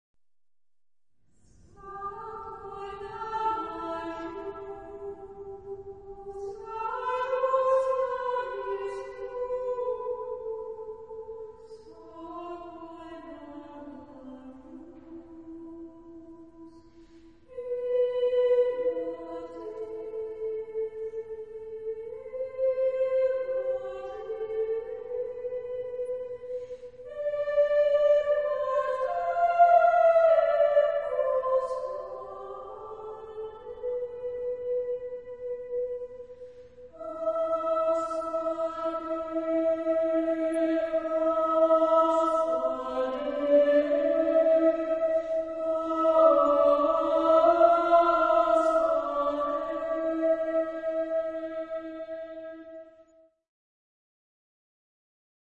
Sagrado ; Concierto ; Cantata
solemne ; meditabundo ; majestuoso ; entrañablemente
SSAA (8 voces Coro femenino ) Solistas : ssa (3 solista(s) )
Tonalidad : la menor